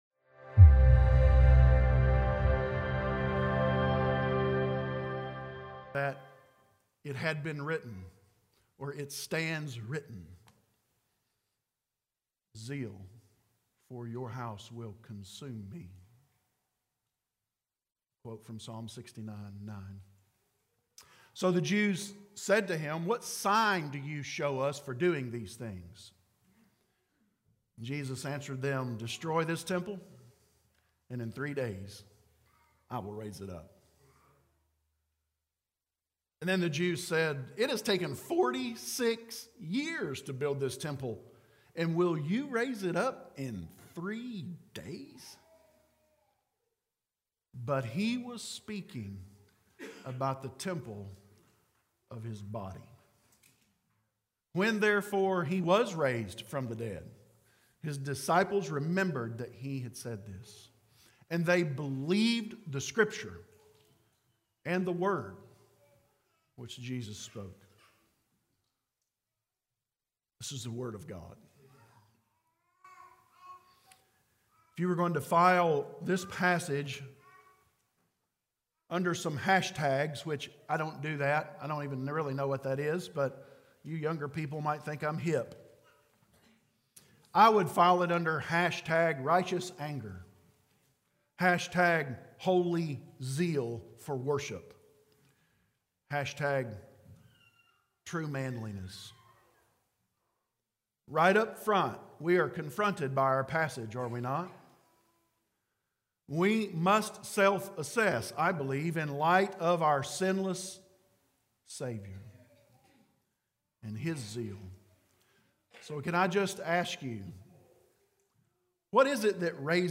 Corydon Baptist Church - A Christian Family of Disciple Making Disciples